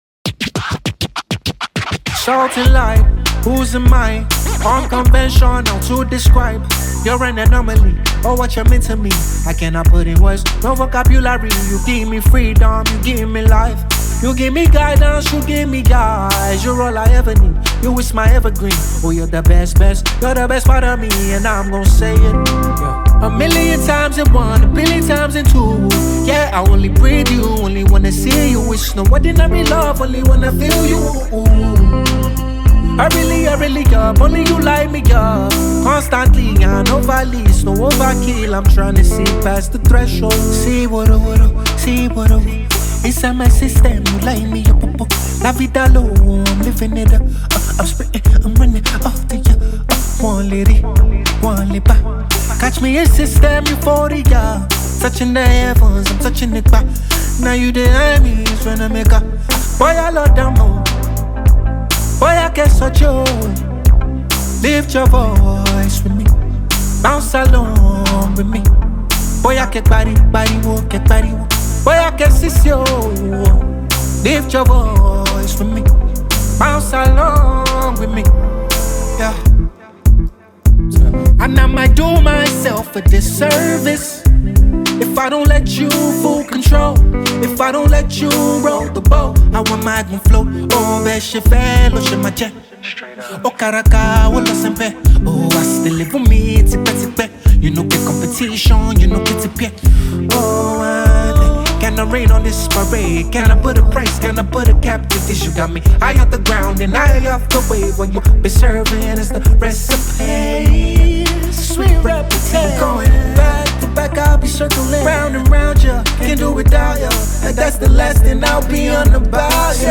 January 22, 2025 Publisher 01 Gospel 0